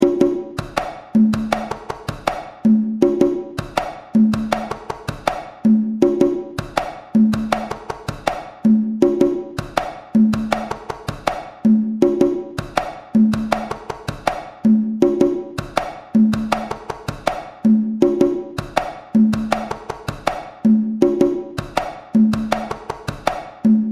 GENERAL INFO Jibaro music is a traditional style from Puerto Rico ORIGIN Puerto Rico RHYTHM INFO Traditional pattern for two drums
GENERAL INFO Jibaro music is a traditional style from Puerto Rico ORIGIN Puerto Rico RHYTHM INFO Traditional pattern for two drums SHEET MUSIC (for key notation click here ) 2-3 SON CLAVE AUDIO FILE AVAILABLE!